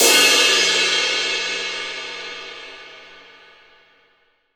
Index of /90_sSampleCDs/AKAI S6000 CD-ROM - Volume 3/Crash_Cymbal1/18_22_INCH_CRASH